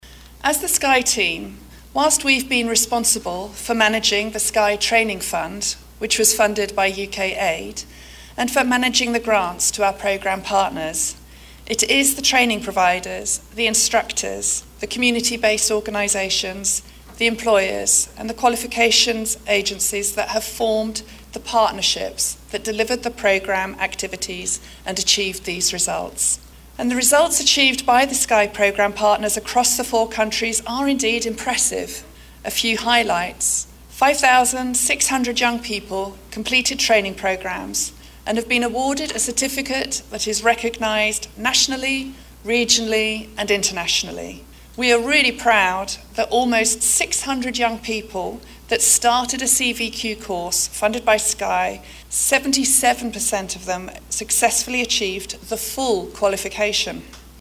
As the project comes to a close, a Presentation and Appreciation Ceremony was held last week.